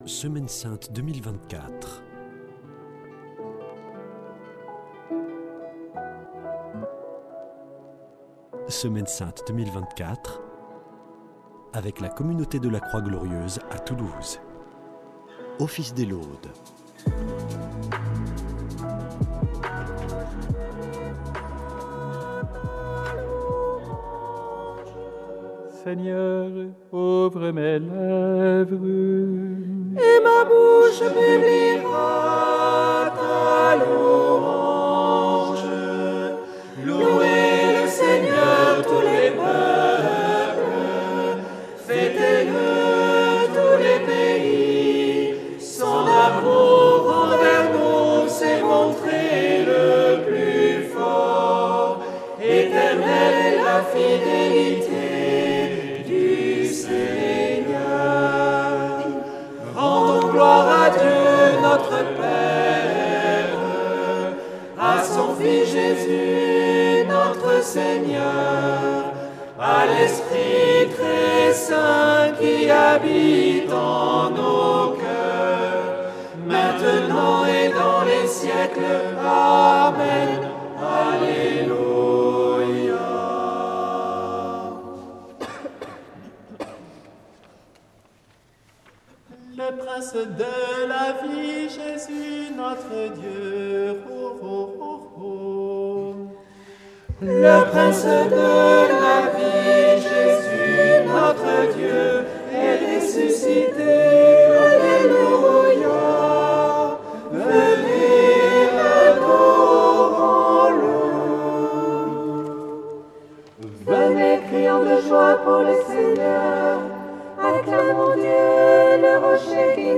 Office des laudes du dimanche de Pâques